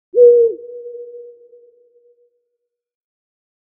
Owl Hoot Sound Effect
A single owl hooting at night in the forest. Perfect for adding mystery, suspense, or a touch of fear to your projects.
Owl-hoot-sound-effect.mp3